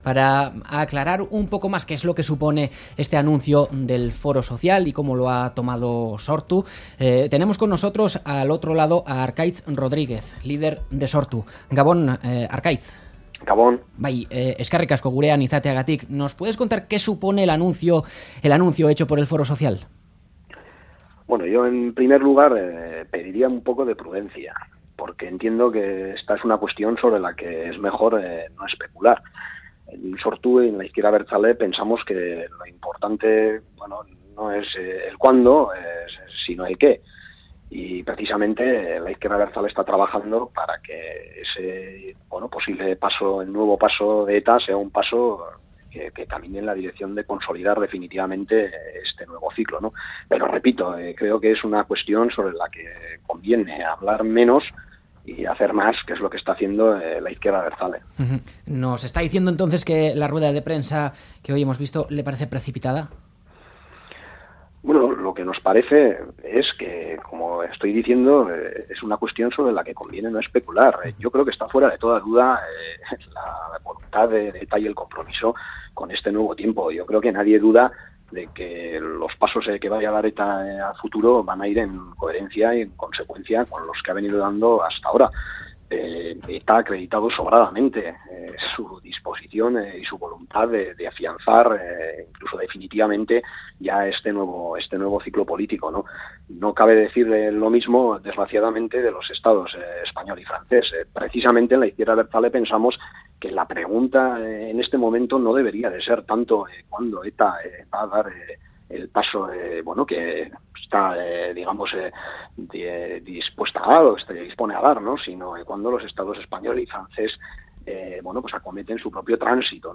Entrevistado en la tertulia de Ganbara